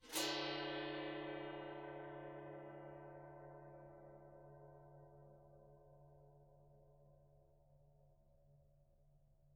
gongscrape_mf.wav